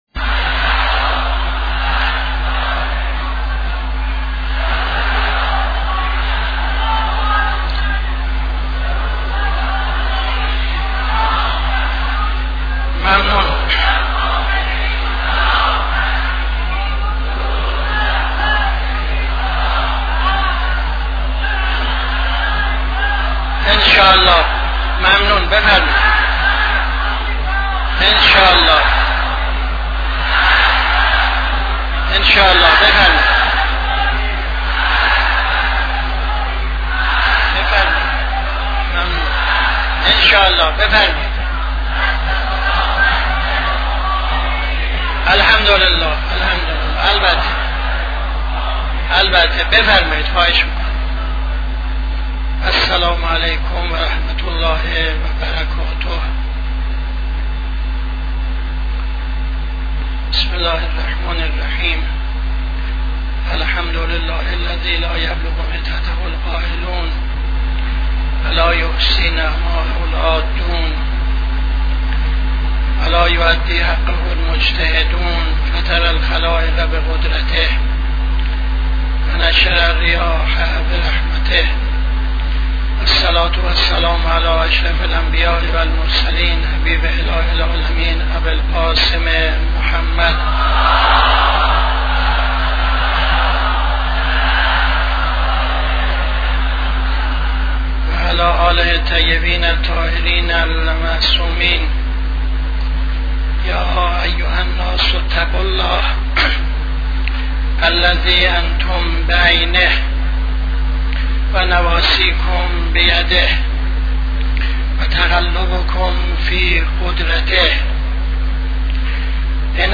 خطبه اول نماز جمعه 17-05-76